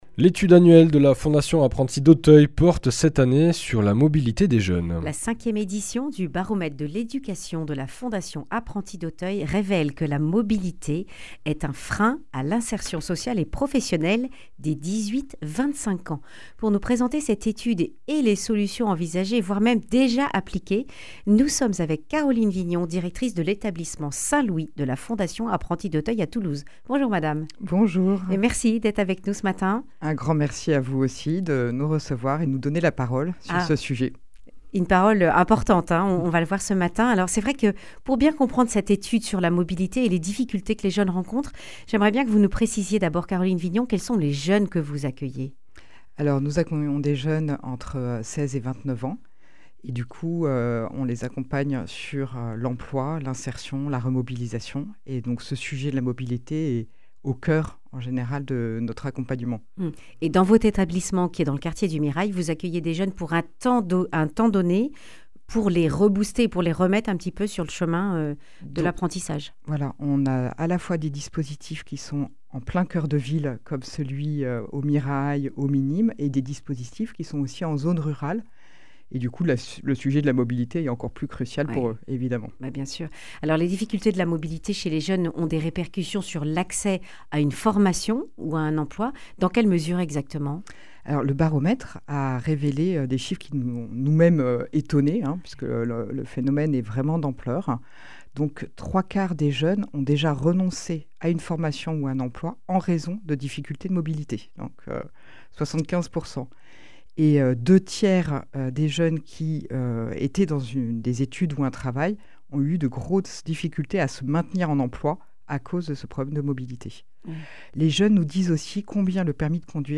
Accueil \ Emissions \ Information \ Régionale \ Le grand entretien \ 77% des jeunes Occitans ont renoncé à une formation ou un emploi en raison (…)